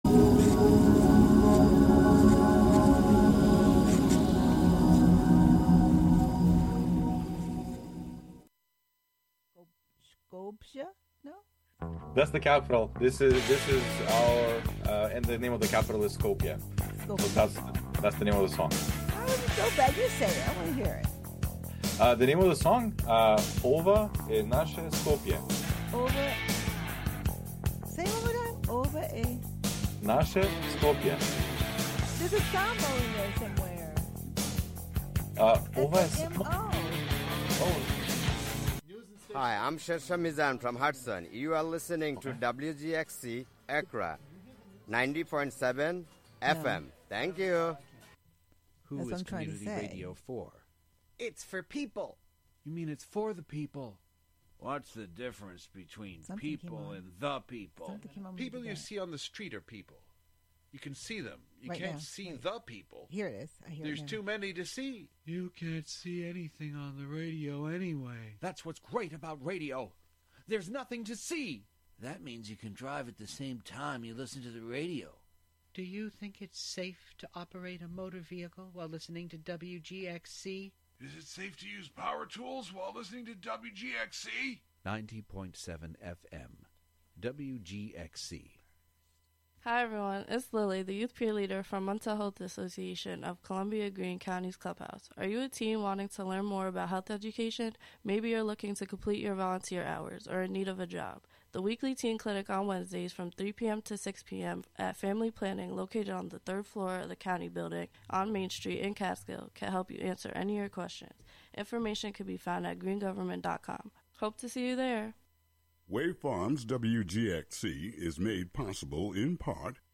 CALL IN LIVE and share your thoughts on immigration, identity, or what “home” means to you.